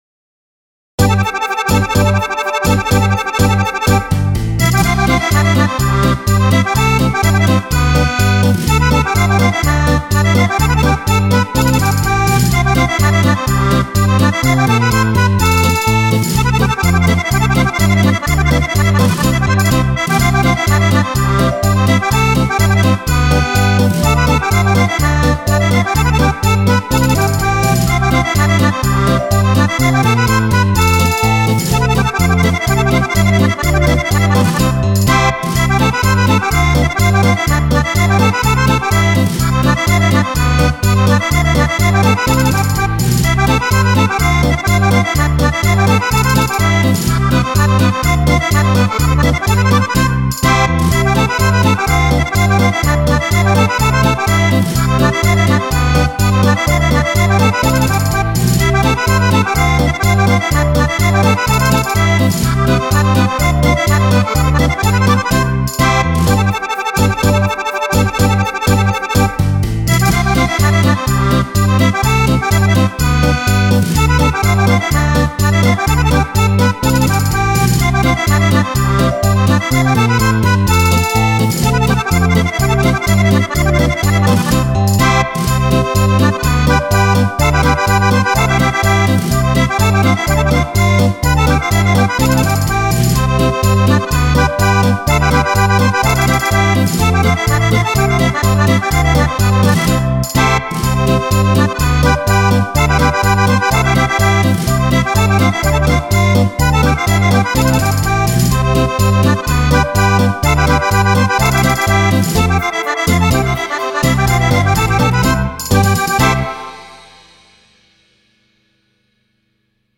5 ballabili per organetto
Polca